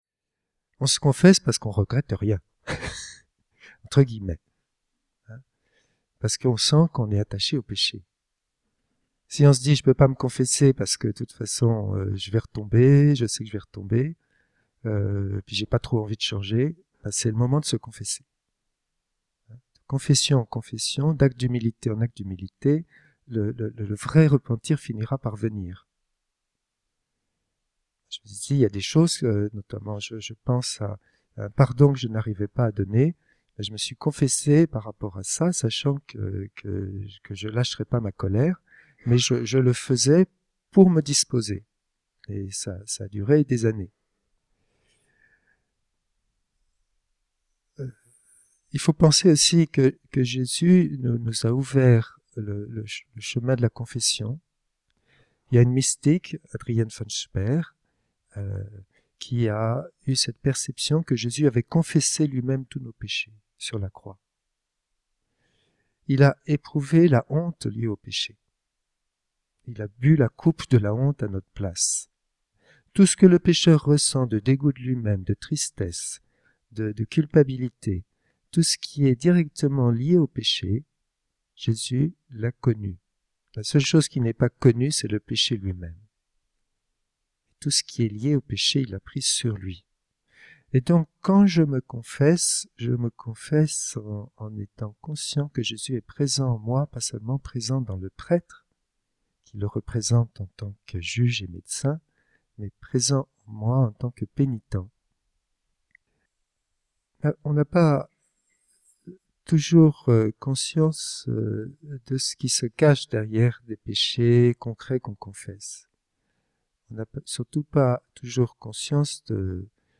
Au fil de ces cinq enseignements nous est proposé un véritable parcours de vie chrétienne.
(Avertissement : la qualité du son de cet enseignement est légèrement altérée)